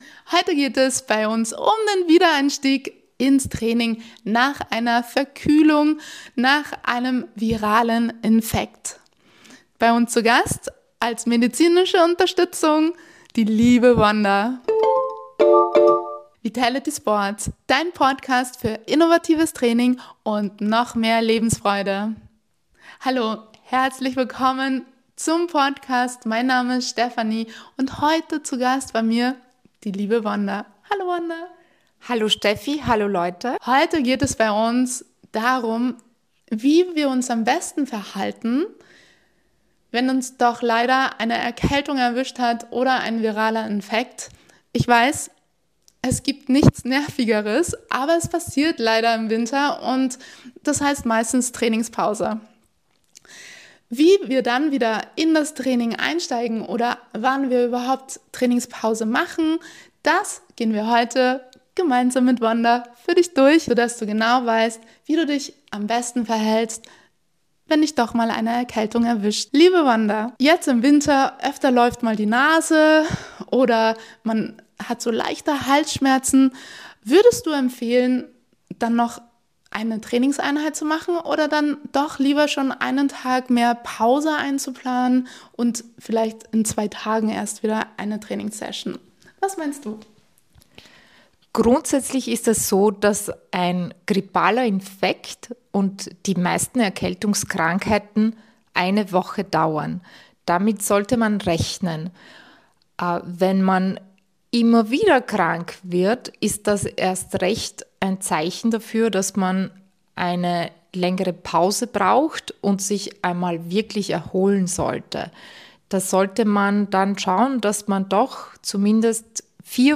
- Interviews mit Gästen: Wir freuen uns, dir interessante Gäste vorzustellen und ihre Erfahrungen, Motivationen und Erfolge mit dir zu teilen.